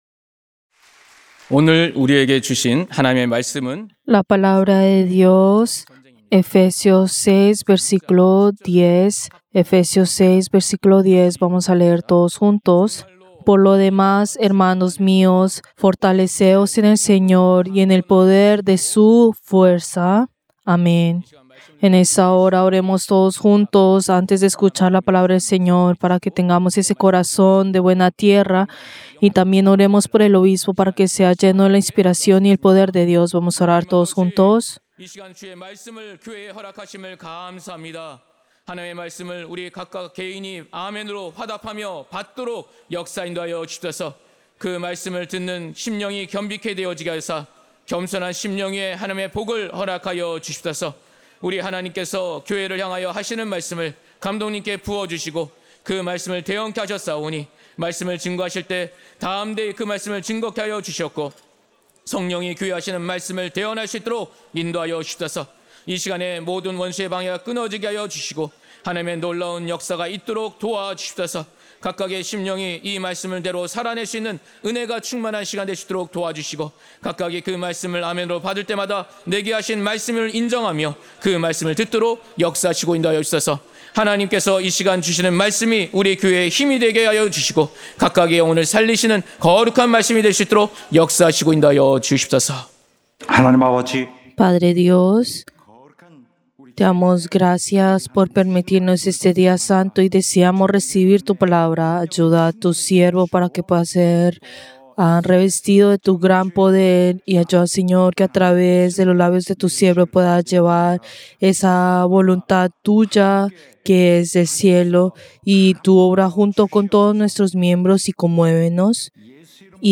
Servicio del Día del Señor del 8 de junio del 2025